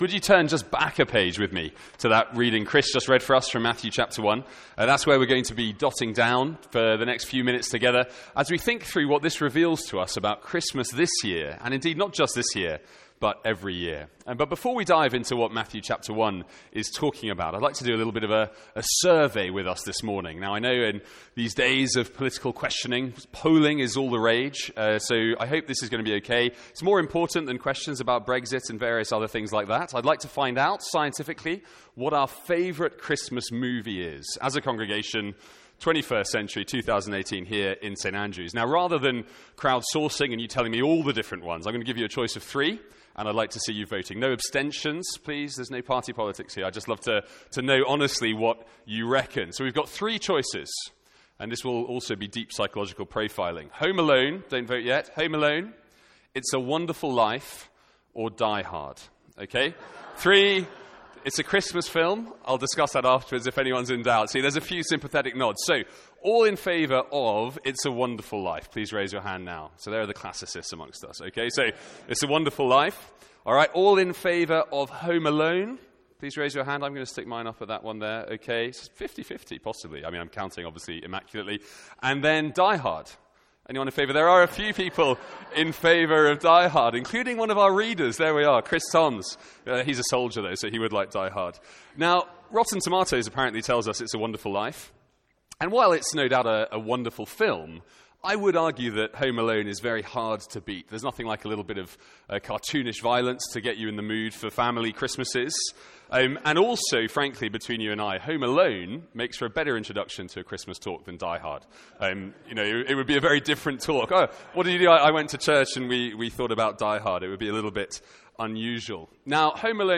Morning Carol Service
From our morning family carol service.